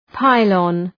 {‘paılən}